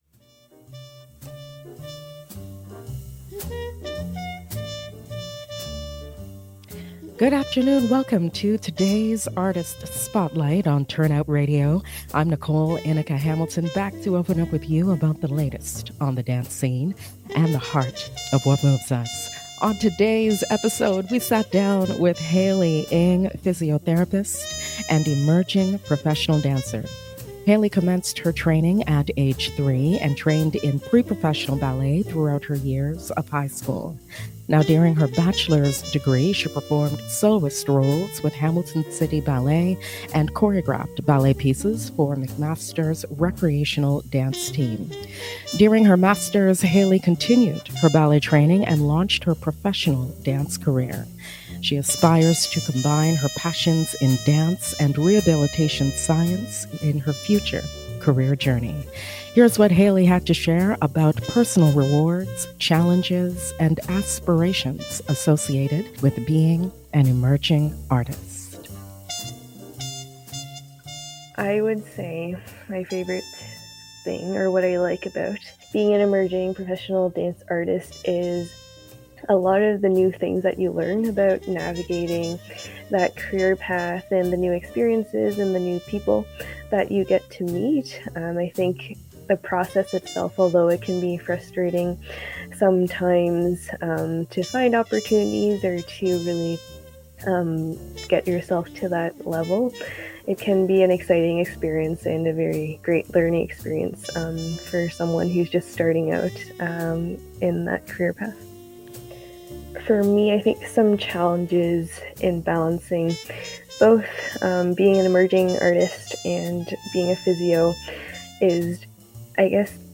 Live on CIUT 89.5 FM